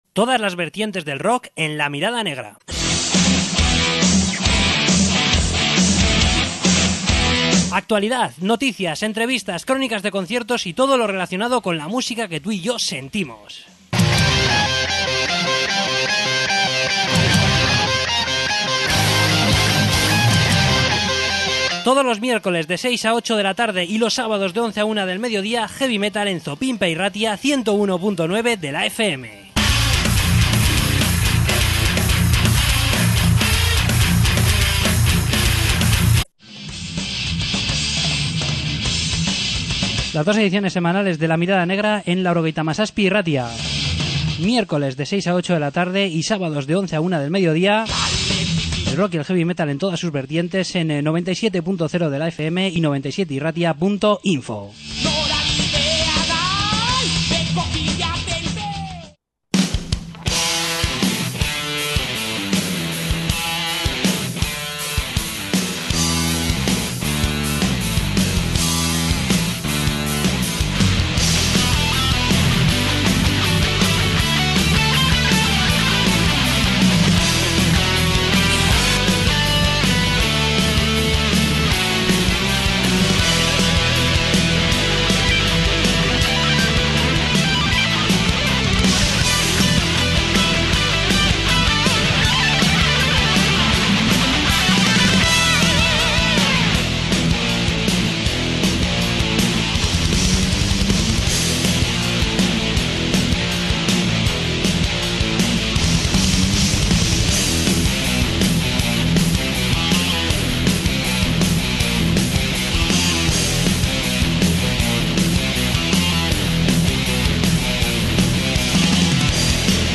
Entrevista con Caravana Underground